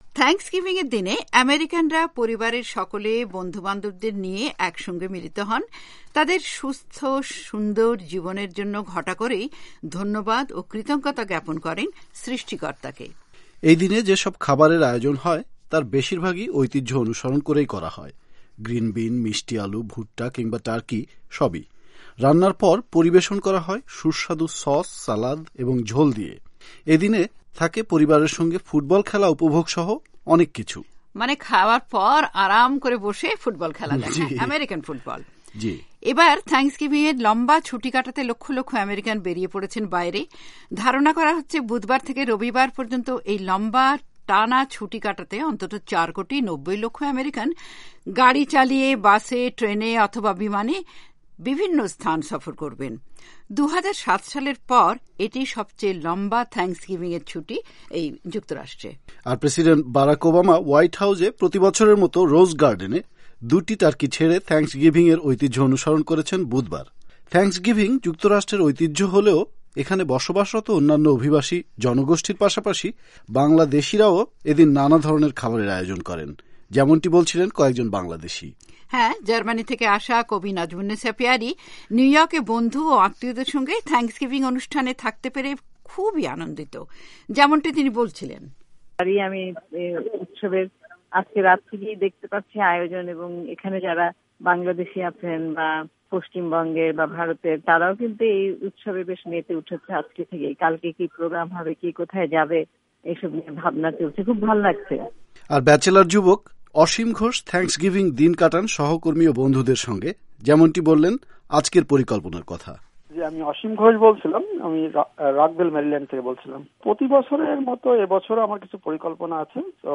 যেমনটি বলছিলেন কয়েকজন বাংলাদেশী।